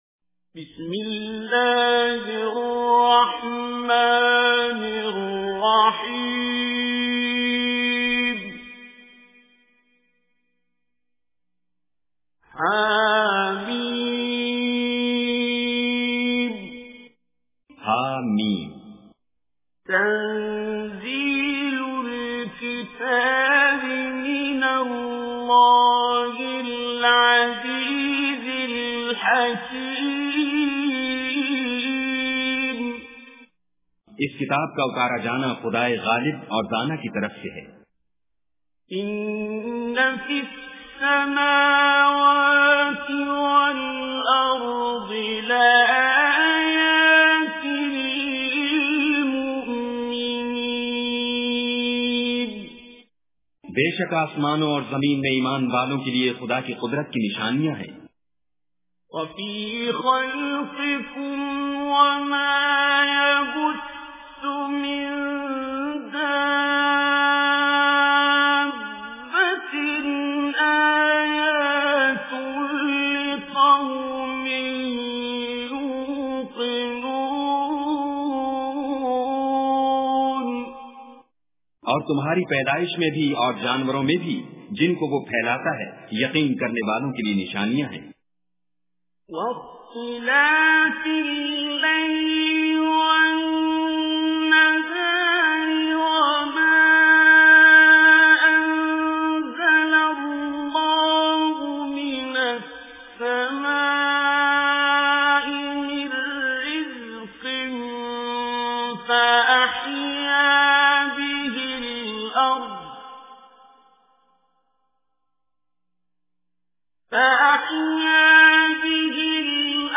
Surah Al Jathiyah Recitation with Urdu Translation
Surah Al-Jathiyah is 45th Surah or chapter of Holy Quran. Listen online and download mp3 tilawat / recitation of Surah Al-Jathiyah in the beautiful voice of Qari Abdul Basit As Samad.